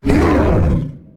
attack3.ogg